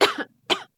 Add cough sound effects
cough_w_0.ogg